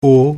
Ääntäminen
Synonyymit Omikron Ääntäminen Tuntematon aksentti: IPA: /ʔoː/ Haettu sana löytyi näillä lähdekielillä: saksa Käännöksiä ei löytynyt valitulle kohdekielelle.